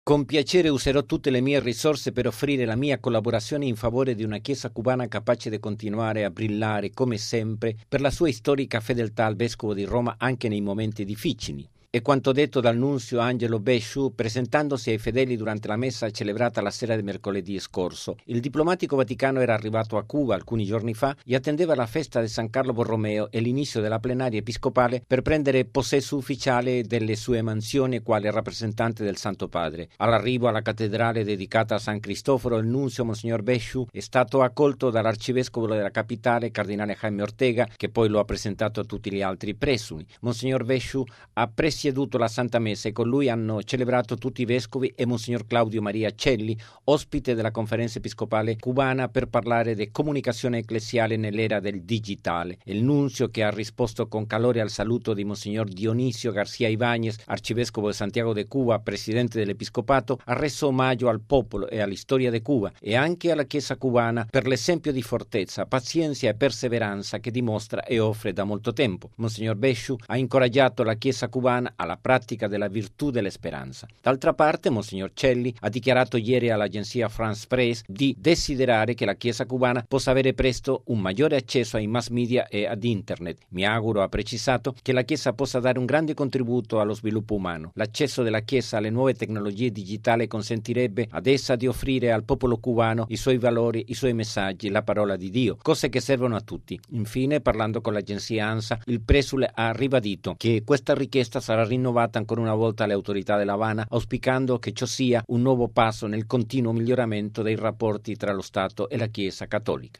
◊   Grande festa, in questi giorni a Cuba, in particolare nell’arcidiocesi dell'Avana, dove a poche ore dall'apertura dell'Assemblea plenaria dell'episcopato, la comunità ecclesiale e i loro pastori hanno dato il benvenuto al nuovo nunzio apostolico Angelo Becciu e al presidente del Pontificio Consiglio delle Comunicazioni Sociali mons. Claudio Maria Celli, nell’isola caraibica per una visita di quattro giorni. Momento culminante è stata la concelebrazione eucaristica nell'antica cattedrale della capitale.